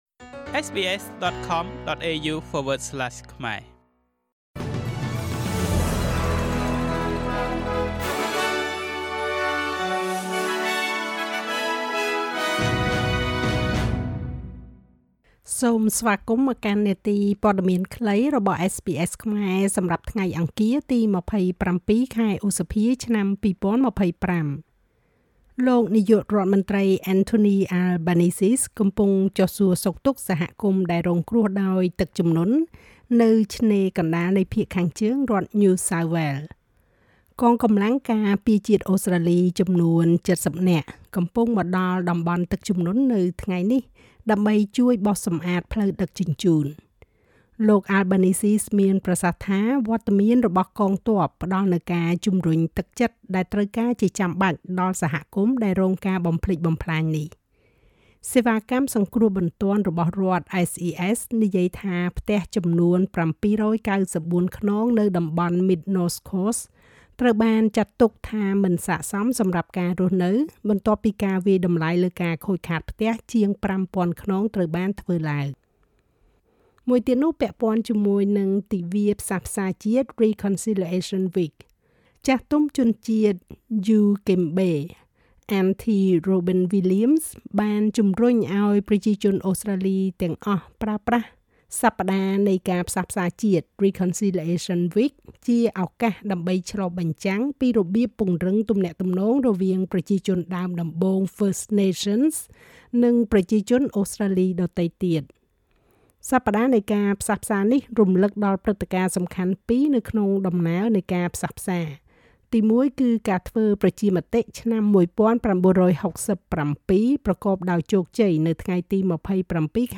នាទីព័ត៌មានខ្លីរបស់SBSខ្មែរ សម្រាប់ថ្ងៃអង្គារ ទី២៧ ខែឧសភា ឆ្នាំ២០២៥